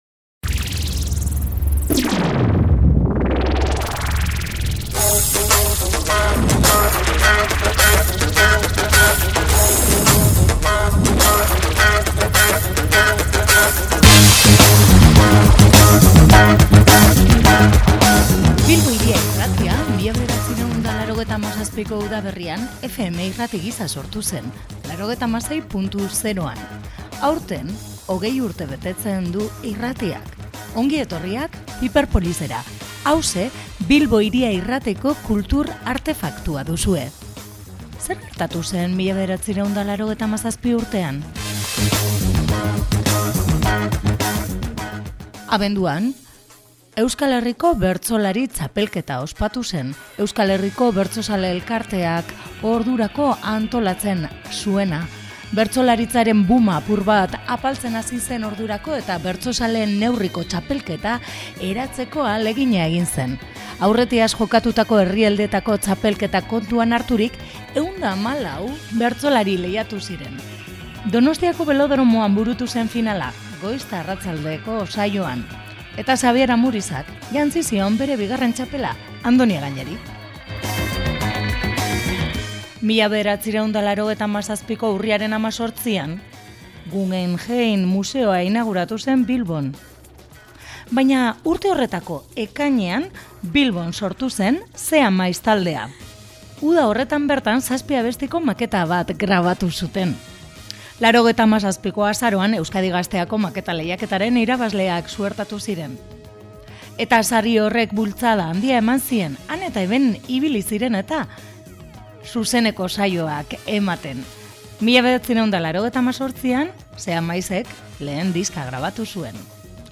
Gaurko saioan bi gonbidatu izan ditugu. Rouge Elea konpaniaren “ Hau da nire ametsen kolorea” ikuskizuna izan dugu hizpide.